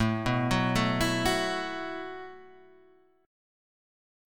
A 6th Suspended 2nd